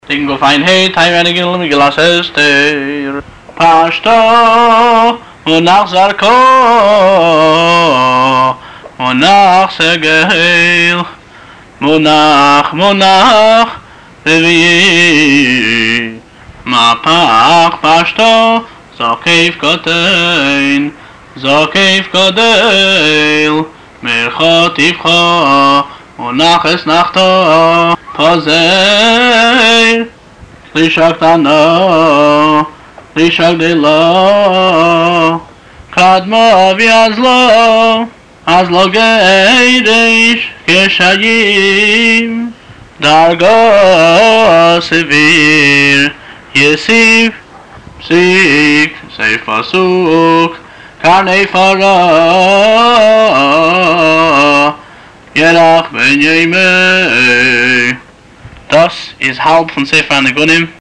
טעמי הנגינה - נוסח חב"ד